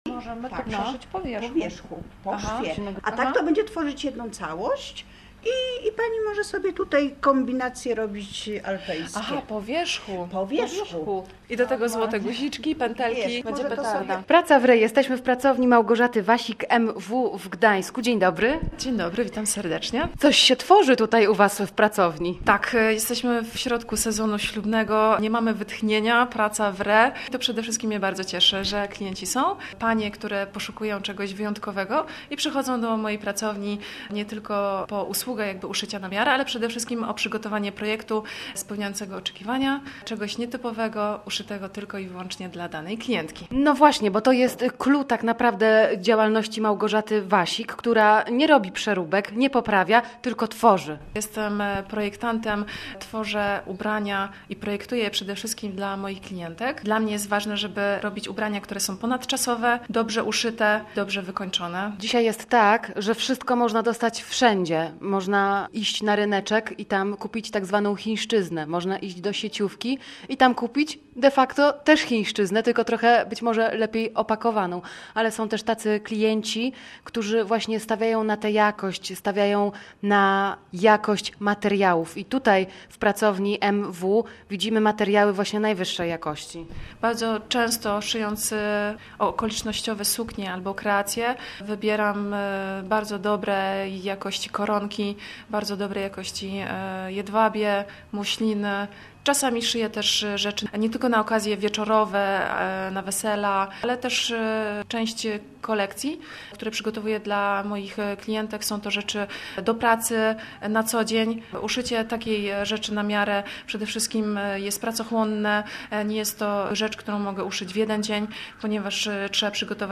Zapraszamy do wysłuchania rozmowy z projektantką.